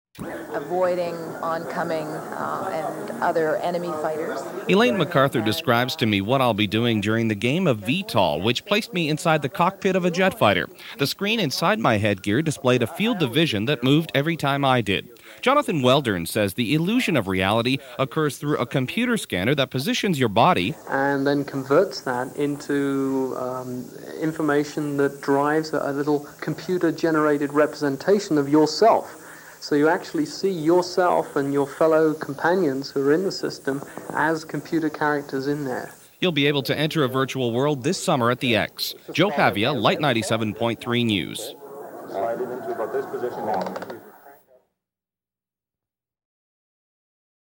My assignment was to conduct an interview and try out the product and present it in a radio story for the newscast, and a feature for the station’s newsmagazine program.